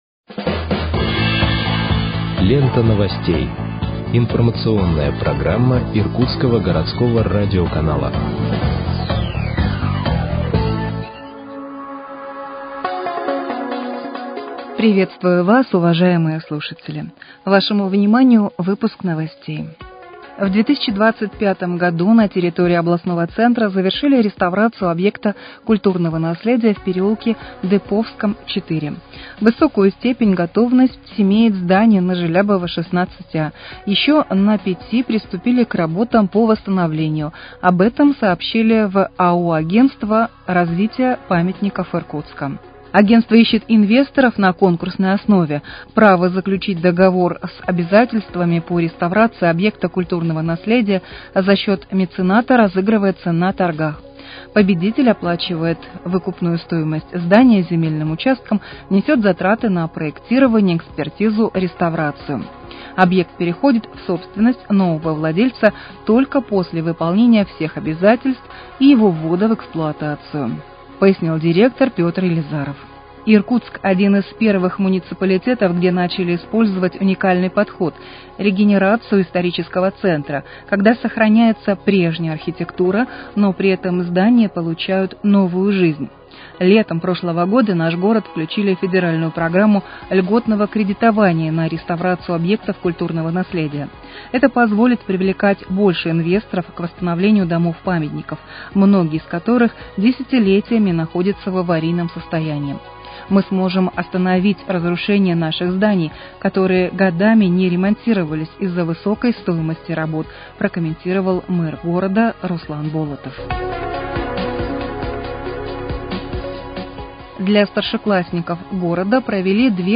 Выпуск новостей в подкастах газеты «Иркутск» от 2.02.2026 № 2